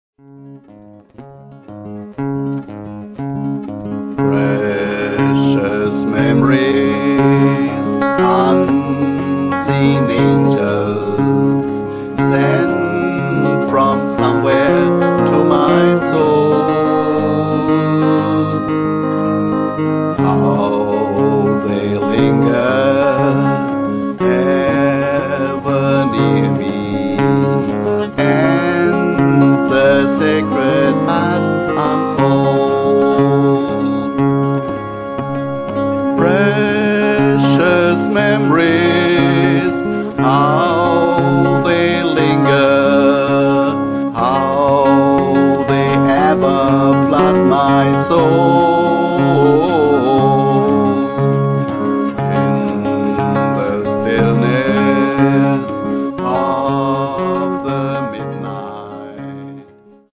Gospels im Country-Style
Seit 2009 mache ich Studioaufnahmen.